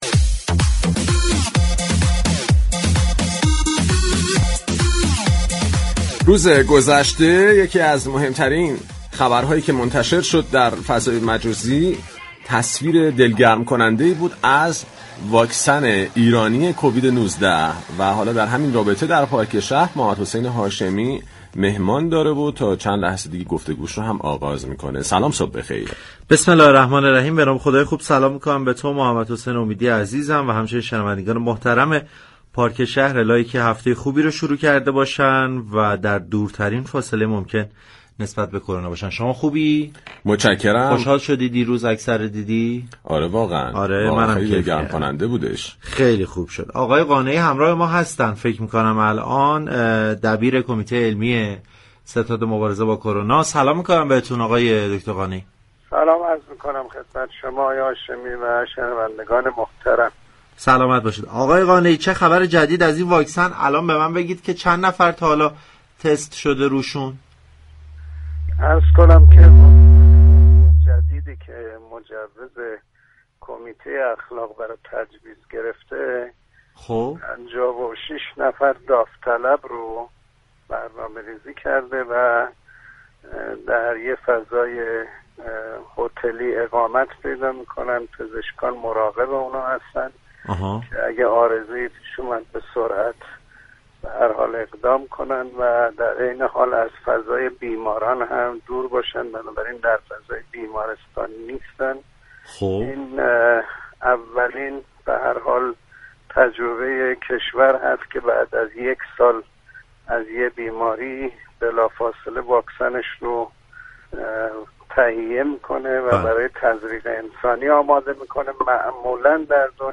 به گزارش پایگاه اطلاع رسانی رادیو تهران،مصطفی قانعی دبیر كمیته علمی ستاد مبارزه با كرونا در گفتگو با برنامه پارك شهر گفت: 56 نفر داوطلب برای تست فاز انسانی واكسن كرونای ایرانی كه مجوز كمیته اخلاق را گرفته است انتخاب شده اند .